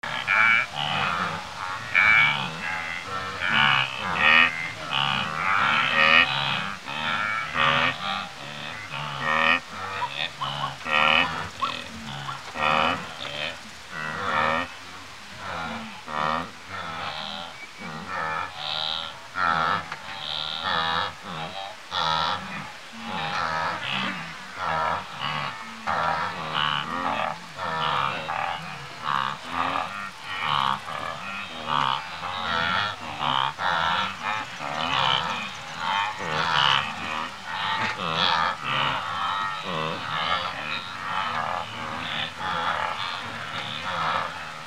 Africa Wildlifewild Animals Gr